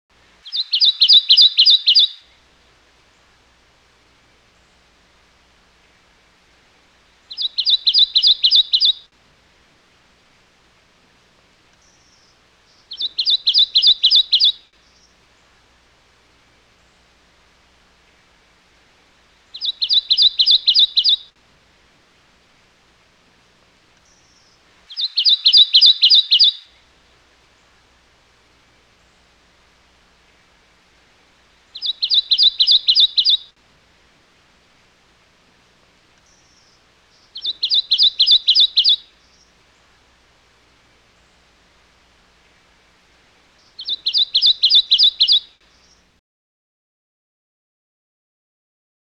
Kentucky Warbler Sound
animal